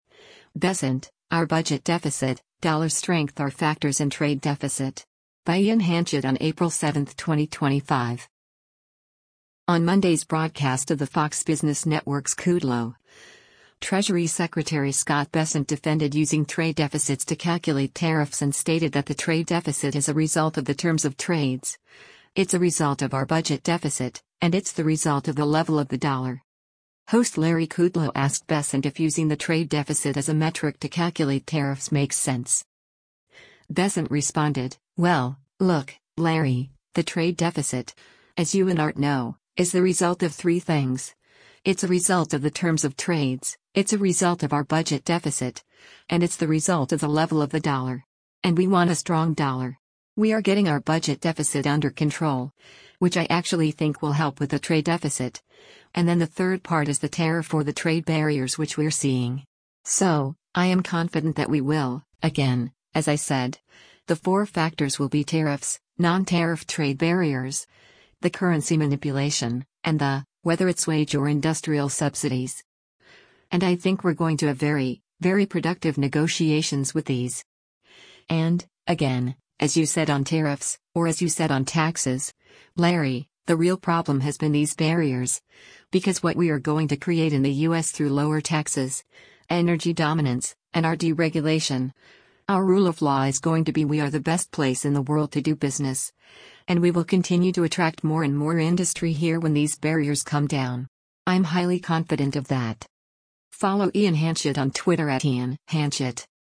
On Monday’s broadcast of the Fox Business Network’s “Kudlow,” Treasury Secretary Scott Bessent defended using trade deficits to calculate tariffs and stated that the trade deficit is “a result of the terms of trades, it’s a result of our budget deficit, and it’s the result of the level of the dollar.”
Host Larry Kudlow asked Bessent if using the trade deficit as a metric to calculate tariffs makes sense.